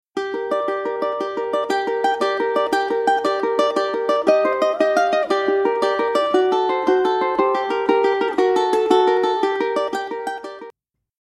traditionnel